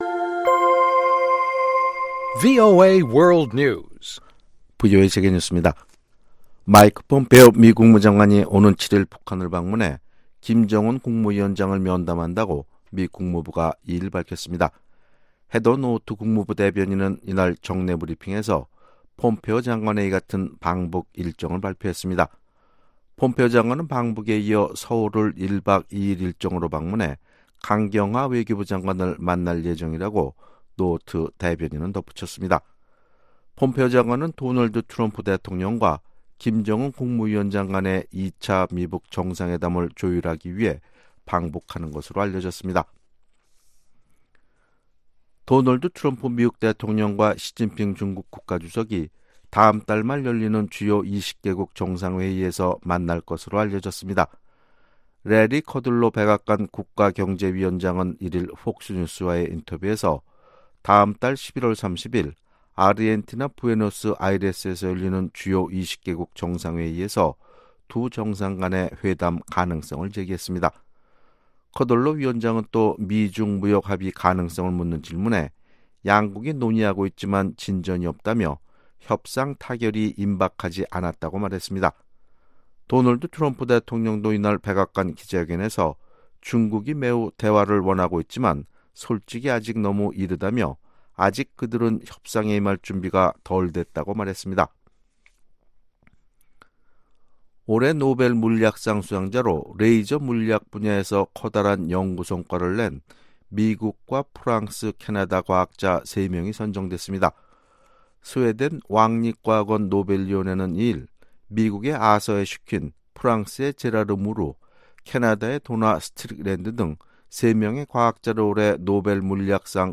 VOA 한국어 아침 뉴스 프로그램 '워싱턴 뉴스 광장' 2018년 10월 3일방송입니다. 한국 국방부는 남북한이 1일부터 시작한 강원도 철원과 공동경비구역(JSA)에서의 지뢰제거는 긴장완화를 위한 시작의 의미라고 밝혔습니다. 미국인 10명 가운데 8명은 북한 비핵화의 상응조치로 북한과의 수교를 꼽았습니다.